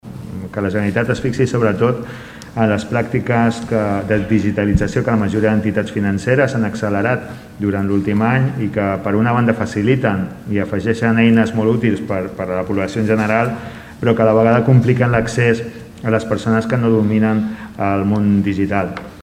El Ple de l’Ajuntament de Tordera va aprovar una moció per controlar la digitalització dels serveis bancaris.
A més, afegeixen que s’incorporen eines digitals que no afavoreixen a tothom. Ho explica el regidor Salvador Giralt.